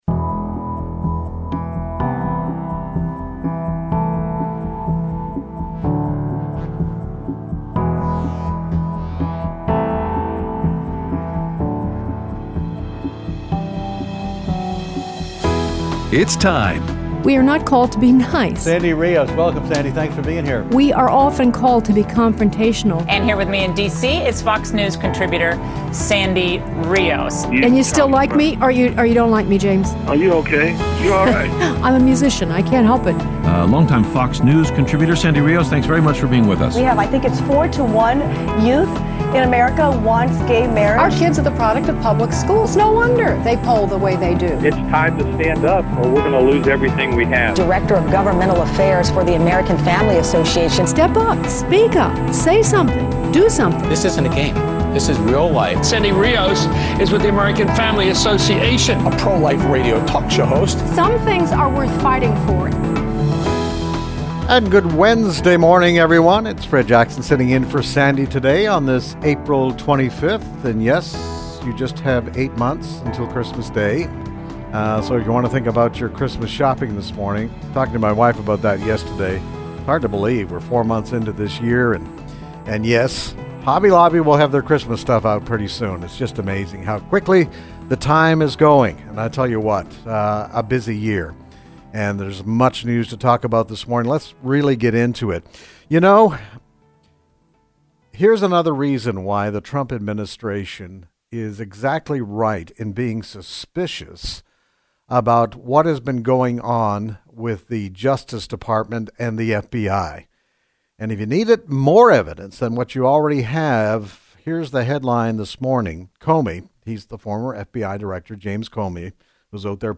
Interviews Gary Bauer on The Left's Attack on Christians
Aired Wednesday 4/25/18 on AFR 7:05AM - 8:00AM CST